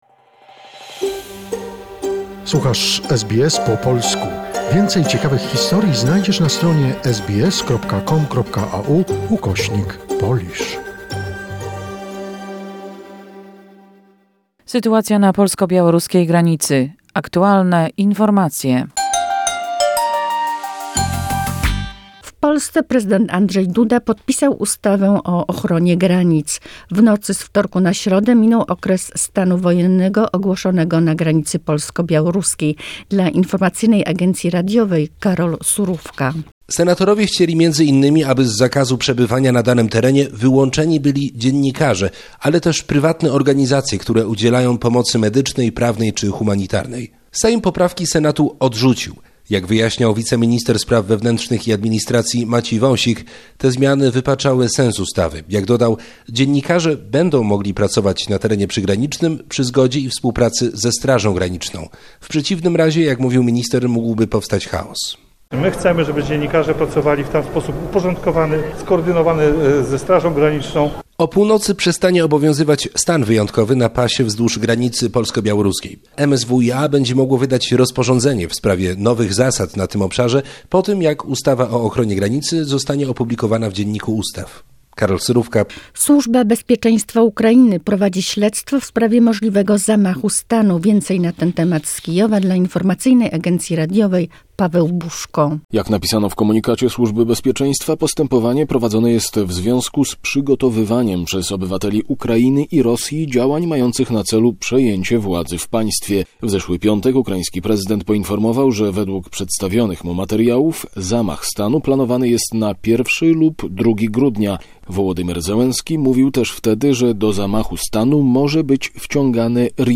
The most recent information and events regarding the Poland-Belarus situation, a short report prepared by SBS Polish.